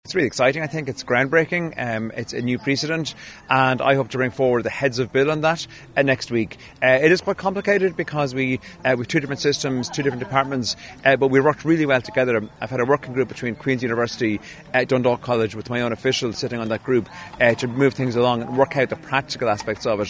Higher Education Minister and Kildare North TD James Lawless says this will begin to benefit students from next September: